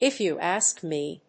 アクセントif you àsk mé